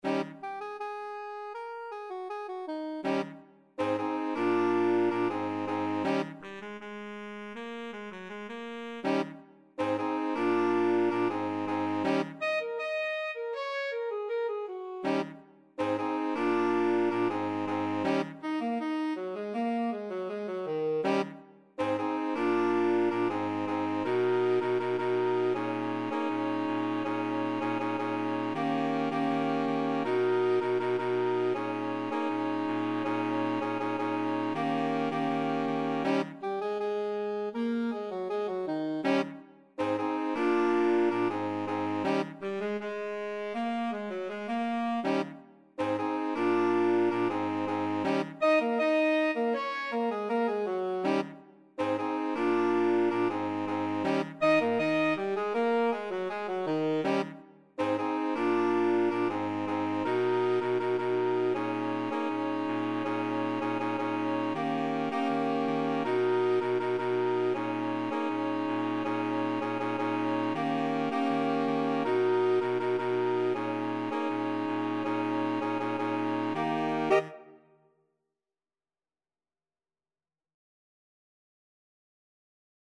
4/4 (View more 4/4 Music)
Pop (View more Pop Saxophone Quartet Music)